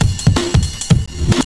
.wav 16 bit 44khz, Microsoft ADPCM compressed, mono,
Lmbrk2.wav Breakbeat 33k